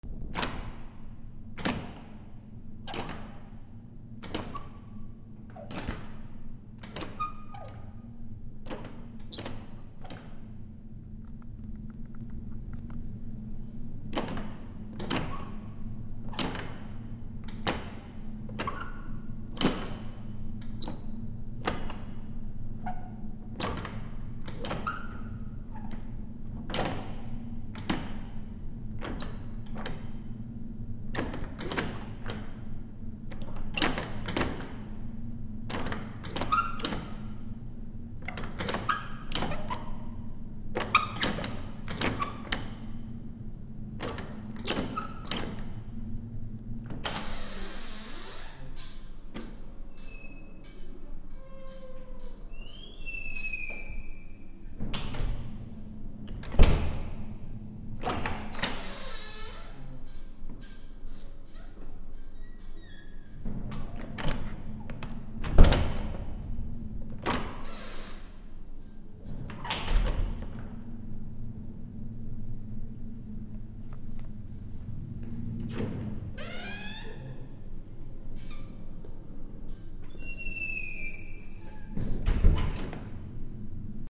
/ K｜フォーリー(開閉) / K05 ｜ドア(扉)
非常階段ドア開け閉め
『チャ コー』